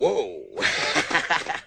Farley Laugh_2